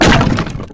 pd_wood2.wav